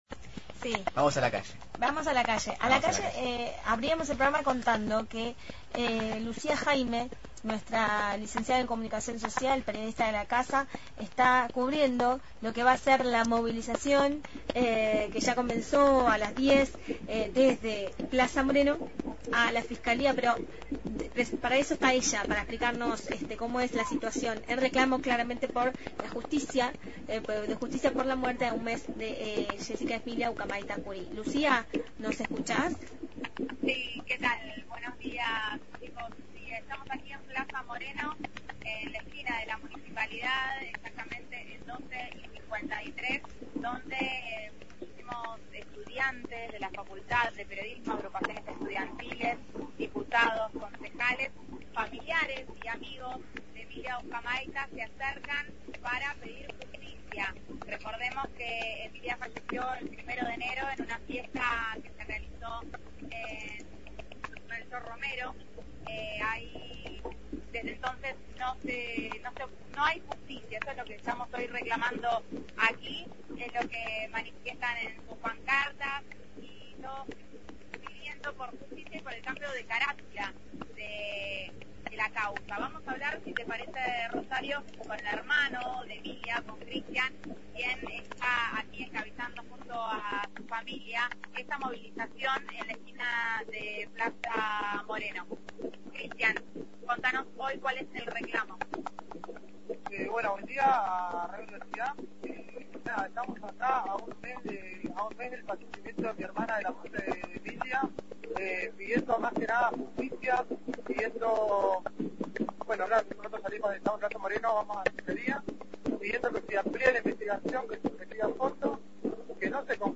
Entrevistas
al diputado bonaerense por el FPV, Gustavo Di Marzio. Programa: Hay que pasar el verano